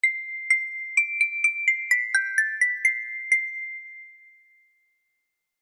• Категория: Рингтон на смс